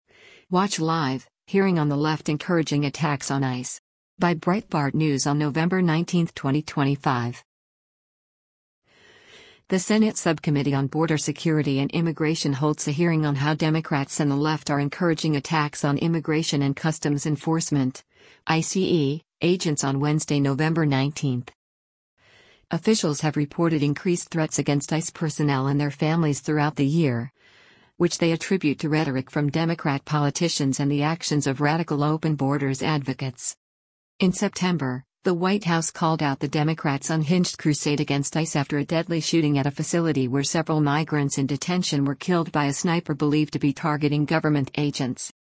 The Senate Subcommittee on Border Security and Immigration holds a hearing on how Democrats and the left are encouraging attacks on Immigration and Customs Enforcement (ICE) agents on Wednesday, November 19.